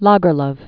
(lägər-ləv, -lœf), Selma Ottiliana Lovisa 1858-1940.